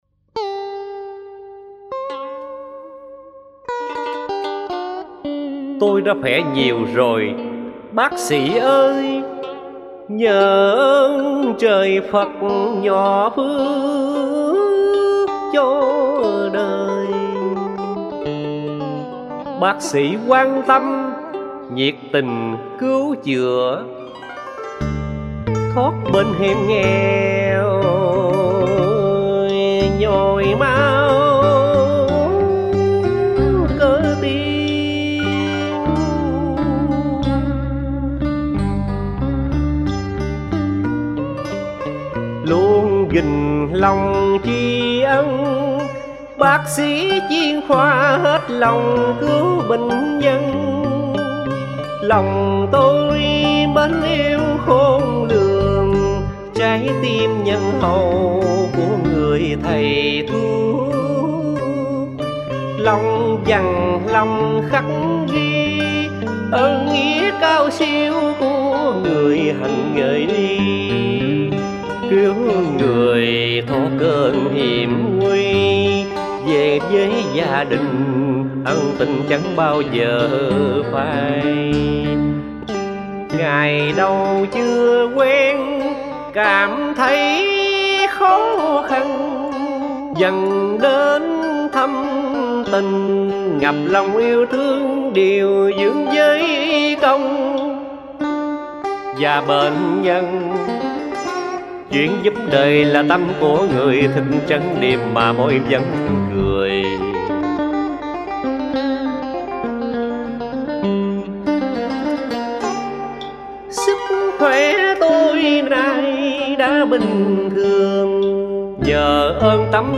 Khi lời ca vọng cổ ngân vang từ trái tim hồi sinh sau hai lần nhồi máu cơ tim